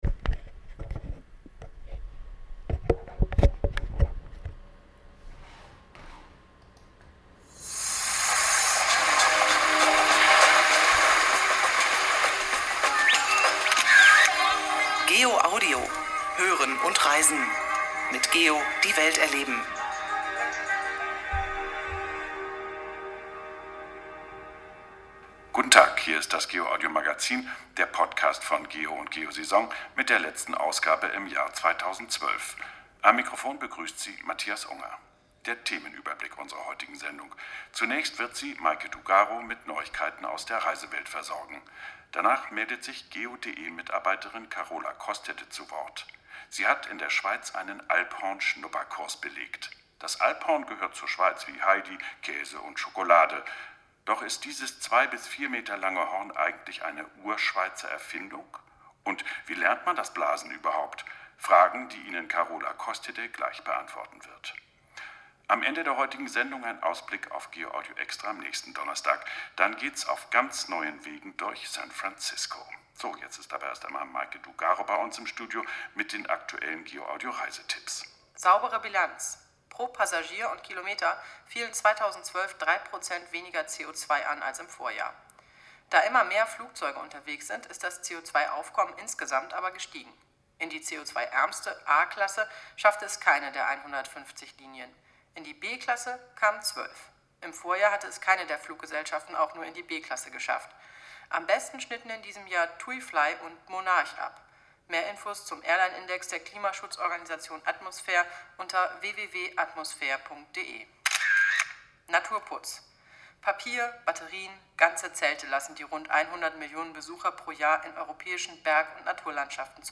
Podcast Mit dem Mikrophon für GEOaudio Magazin unterwegs.
GEOaudiMagazin.Alphorn.mp3